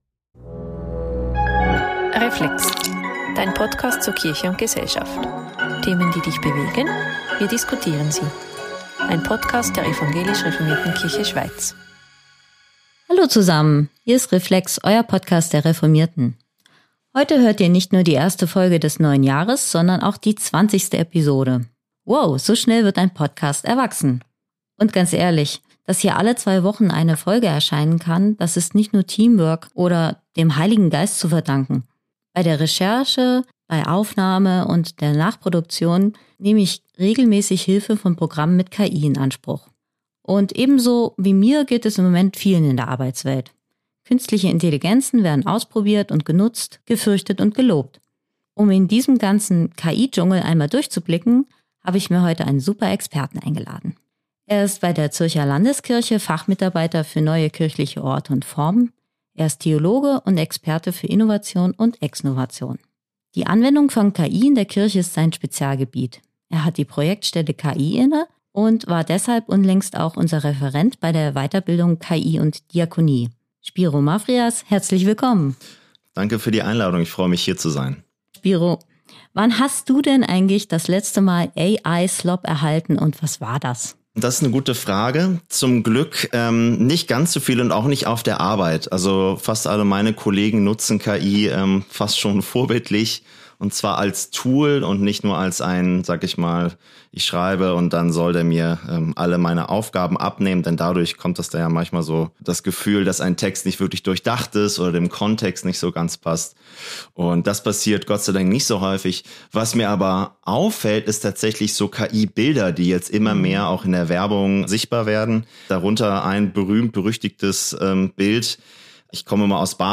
Ein bereichendes Gespräch mit Weitblick und Realismus.